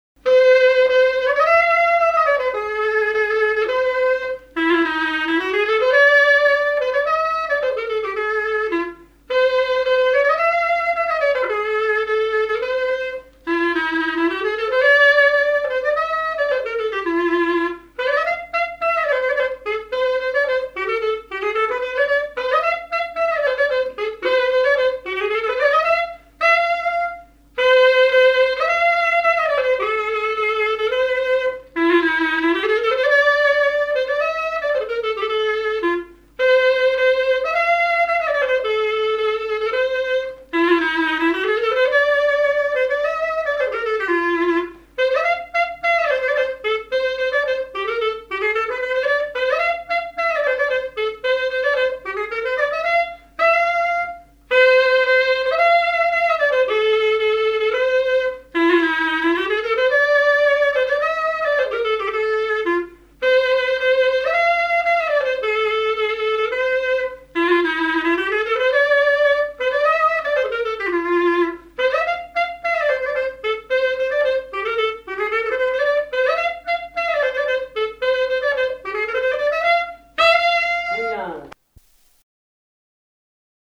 Marche nuptiale
fiançaille, noce
Pièce musicale inédite